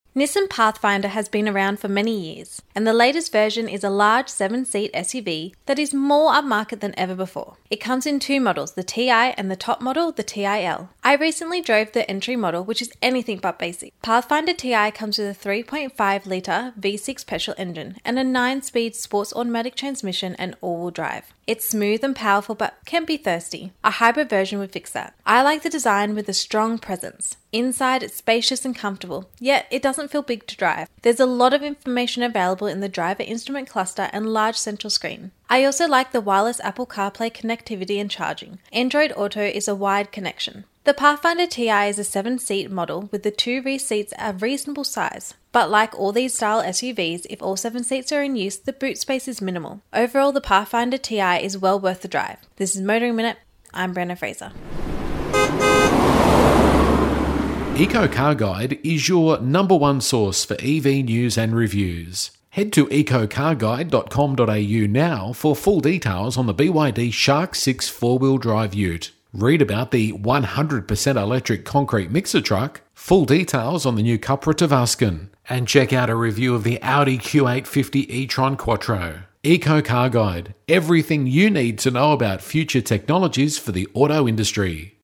Motoring Minute is heard around Australia every day on over 100 radio channels.